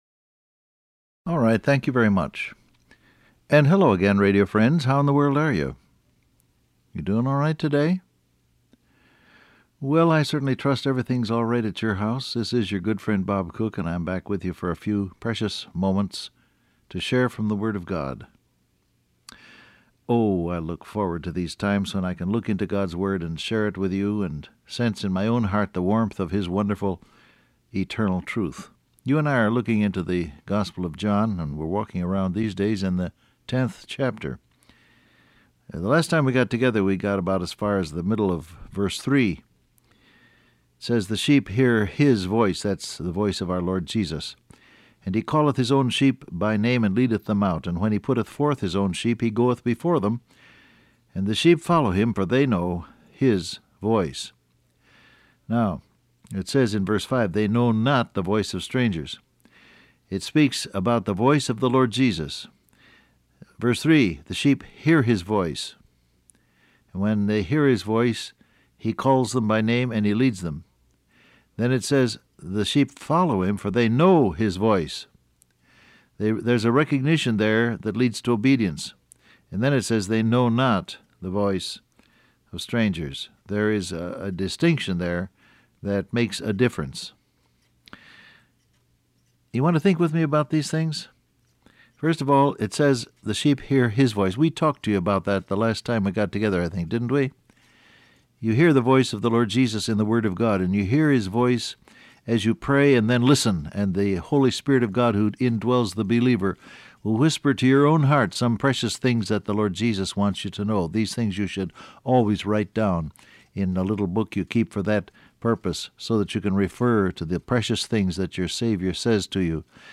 Download Audio Print Broadcast #6879 Scripture: John 10:3 , Proverbs 3:6 Topics: Called By Name , Know His Voice , Acknowledge Him Transcript Facebook Twitter WhatsApp Alright, thank you very much.